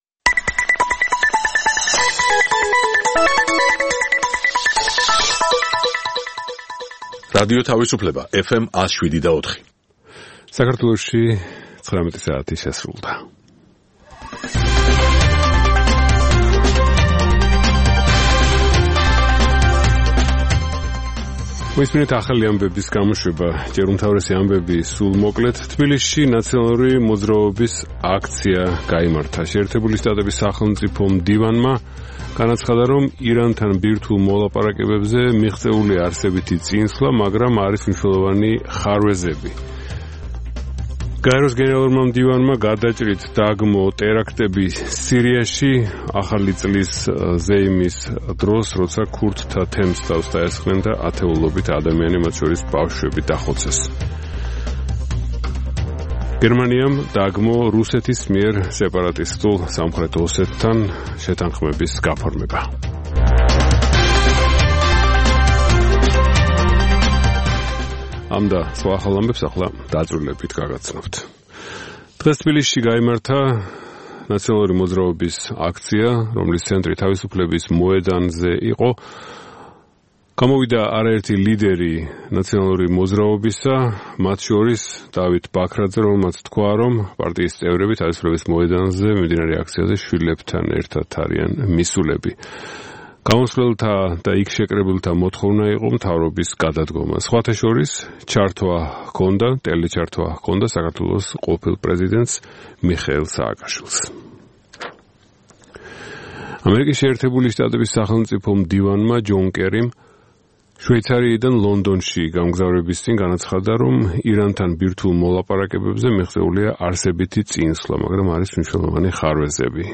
ახალი ამბები (რადიო თავისუფლება) + Music Mix ("ამერიკის ხმა")